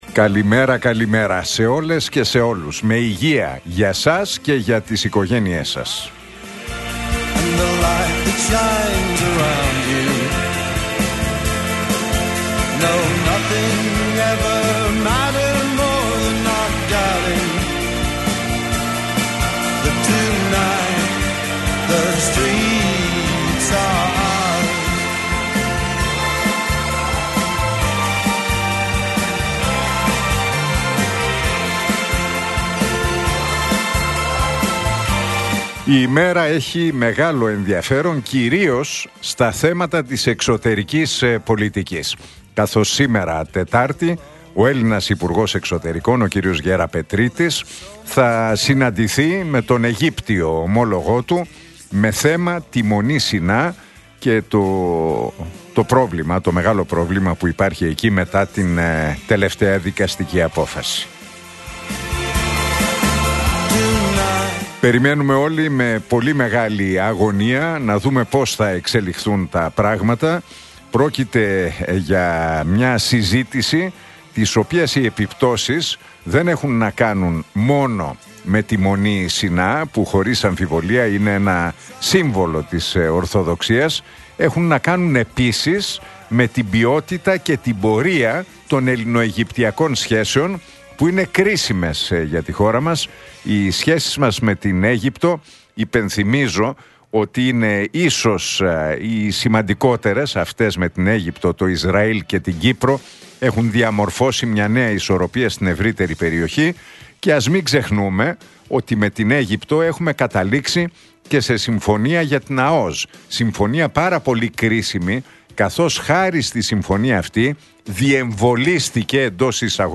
Ακούστε το σχόλιο του Νίκου Χατζηνικολάου στον ραδιοφωνικό σταθμό Realfm 97,8, την Τετάρτη 4 Ιουνίου 2025.